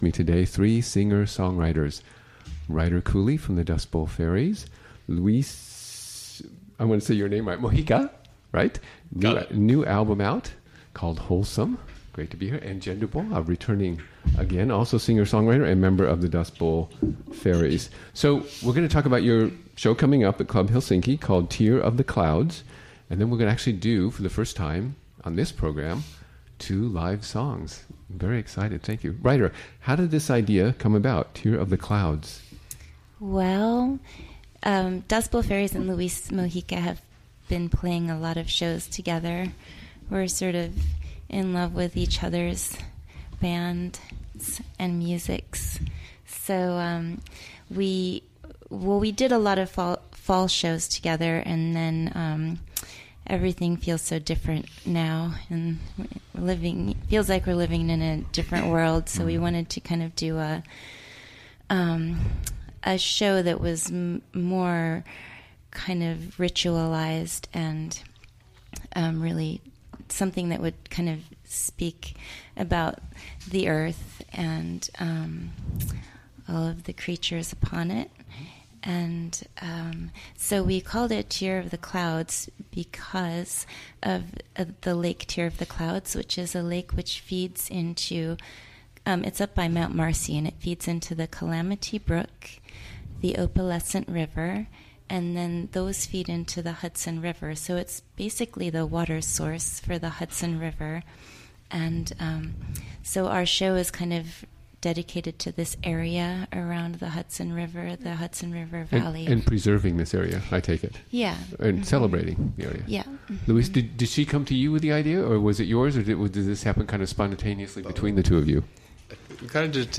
Recorded live during the WGXC Afternoon Show on Tuesday, May 2, 2017.